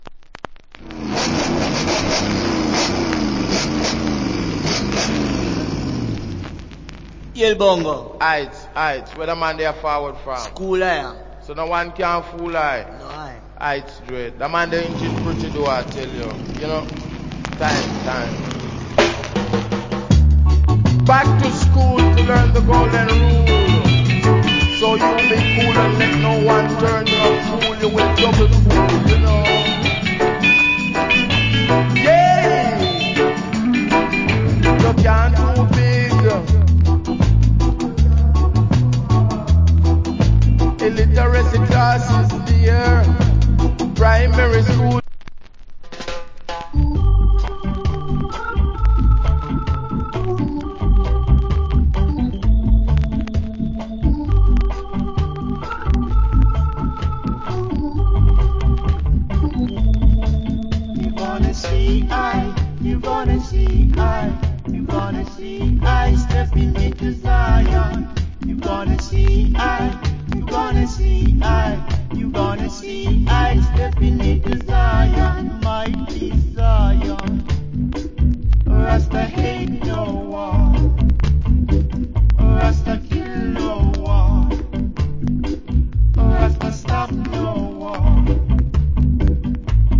Wicked DJ.